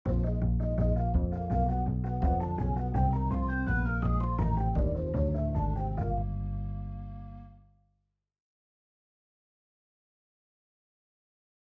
12小節の定番ブルース進行
次に、ブルース進行の5〜8小節目を譜例にしてみます。
サンプル音源　とてもオーソドックスに1〜3小節までブルーノートで弾き切ります。
4小節目はマイナーのⅡ/Ⅴの、ほぼ定番フレーズです。